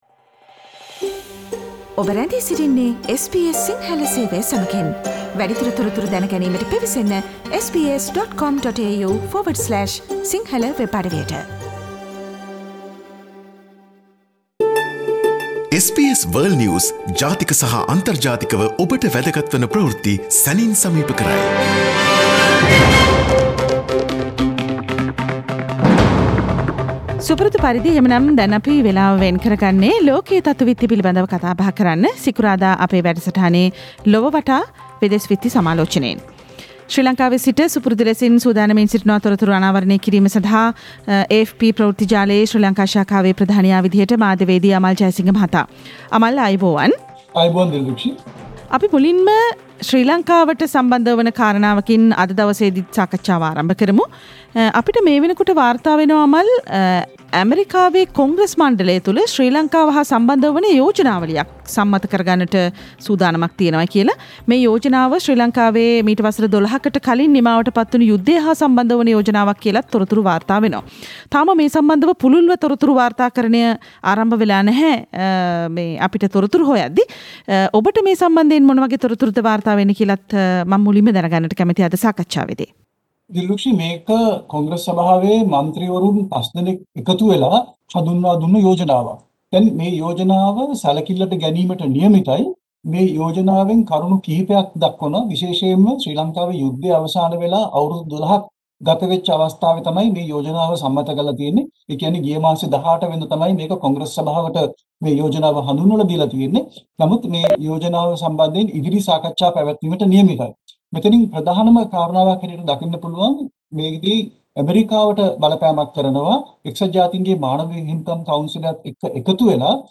Listen to the latest news from around the world this week from our weekly "Around the World" foreign news review.